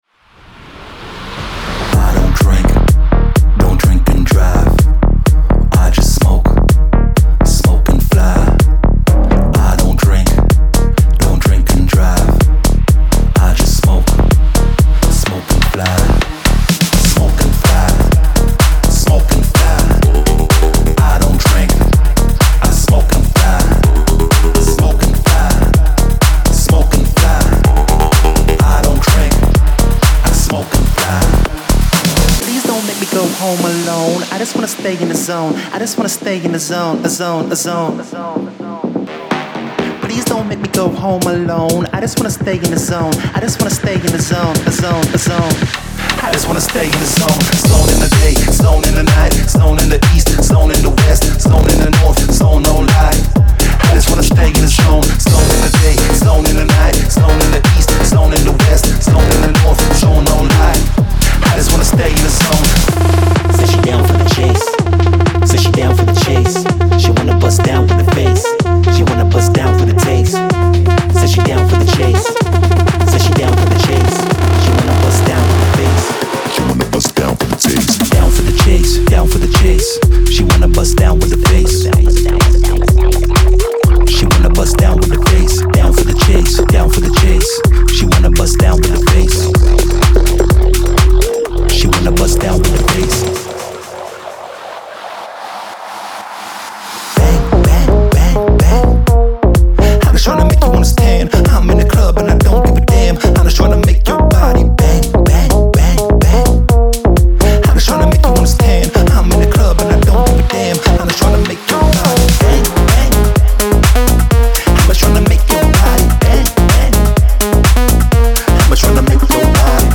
デモサウンドはコチラ↓
Genre:Tech House
126 BPM
44 Bass Loops
69 Melody Loops
46 Vocal Loops (22 Dry, 24 Wet)
40 Percussion Loops